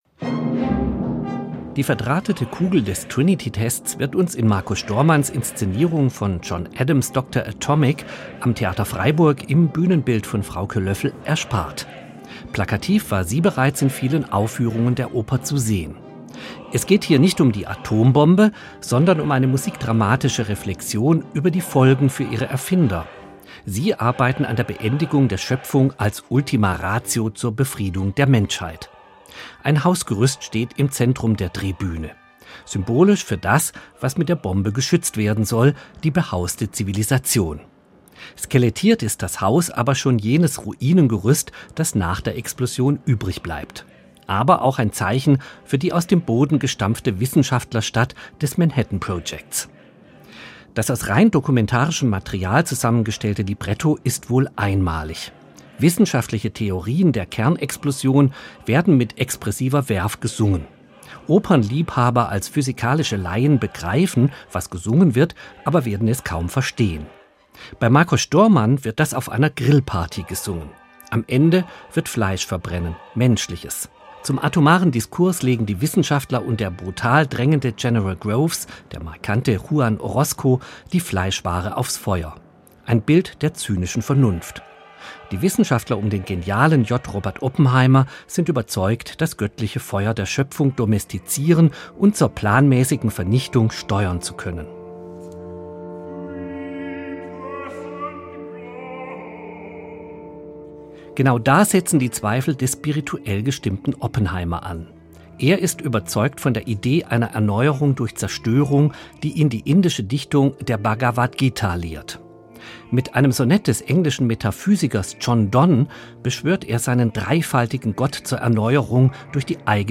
Oper am Theater Freiburg
Wissenschaftliche Theorien der Kernexplosion werden mit expressiver Verve gesungen.
Adams tickende Zeitbombe aus Pizzicati, Bläserschreien, Glocken-, Gongs- und Schlagzeugstößen versandet in der Stimme einer Japanerin, die um Wasser für ihr Kind in der Atomwüste von Hiroshima bittet.